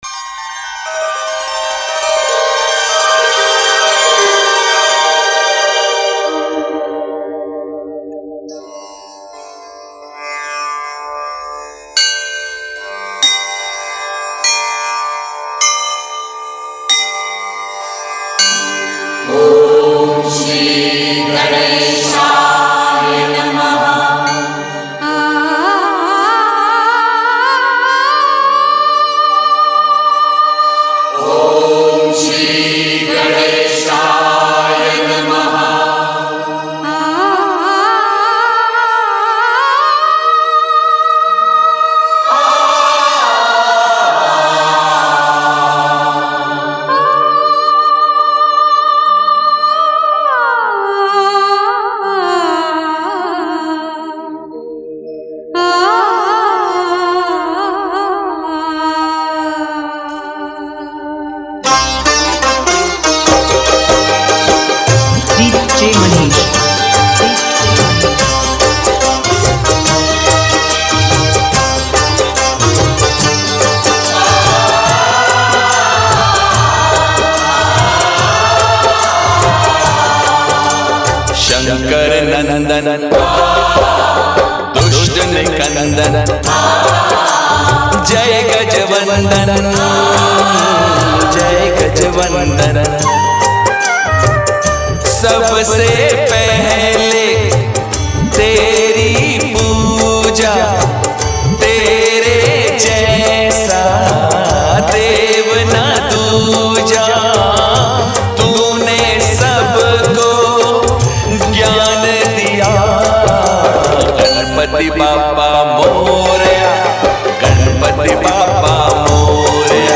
Ganesh Chaturthi Dj Remix Song